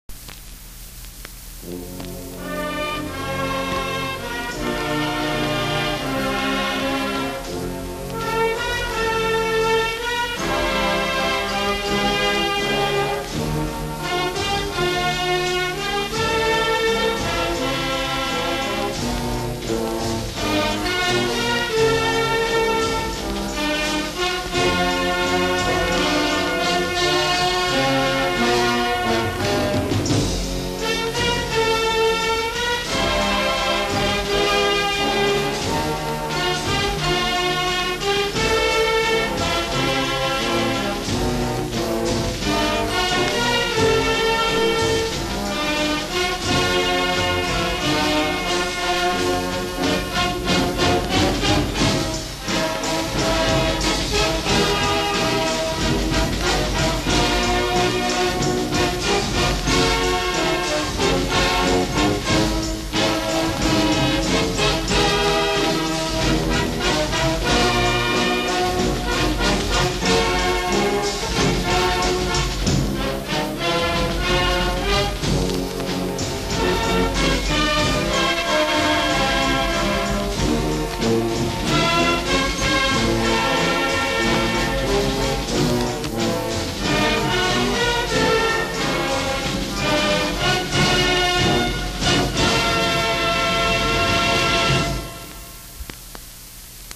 MCC Marching Band